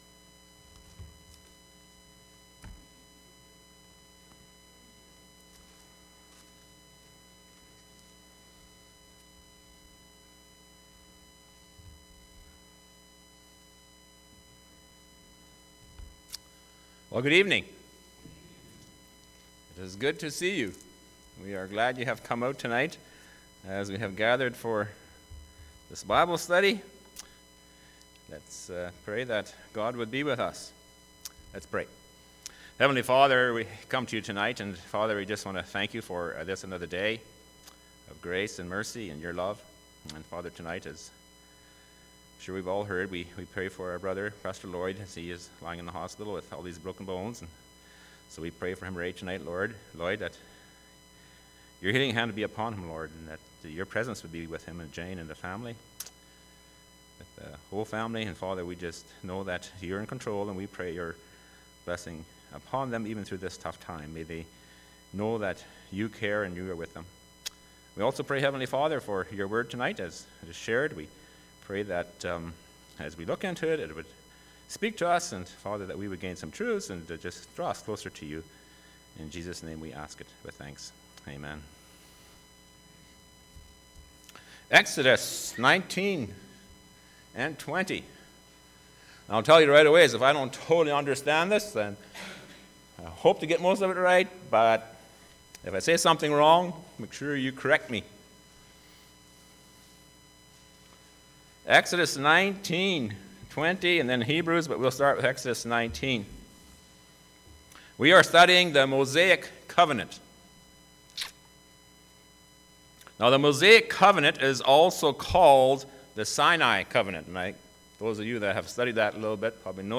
Church Bible Study – Covenants – Mosaic